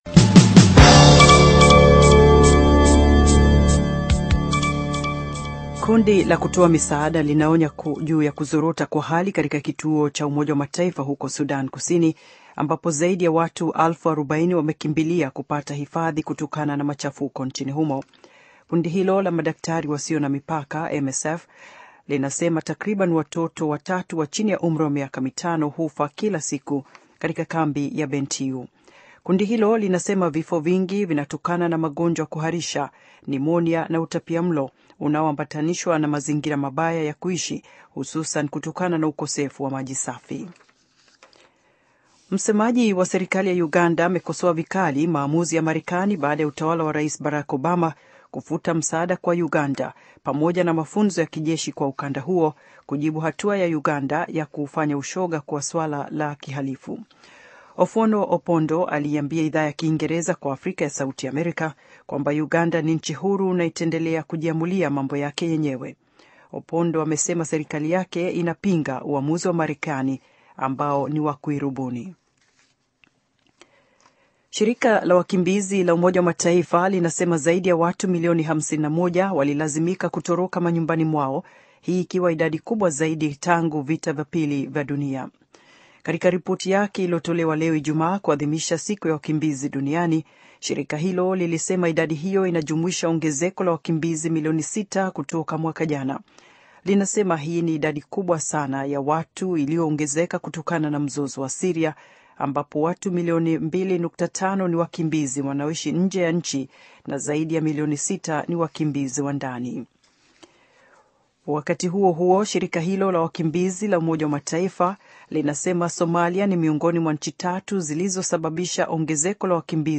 Taarifa ya Habari VOA Swahili - 4:49